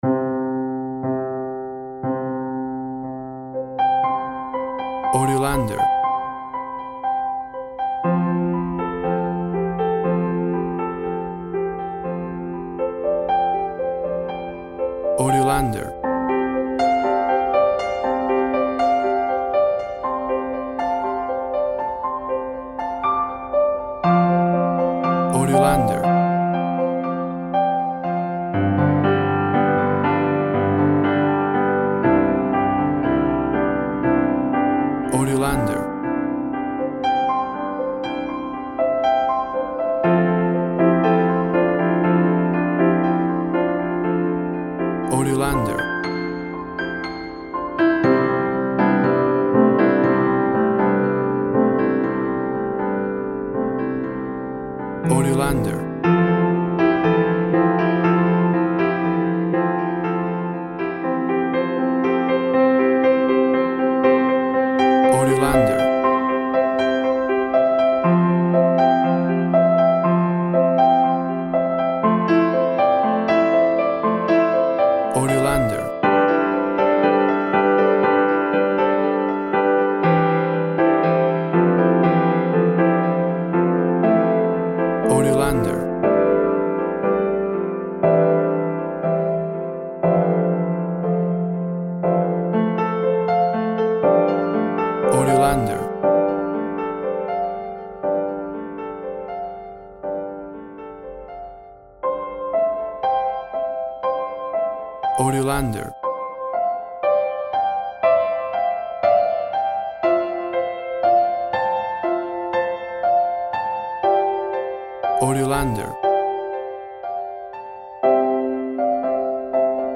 WAV Sample Rate 16-Bit Stereo, 44.1 kHz
Tempo (BPM) 70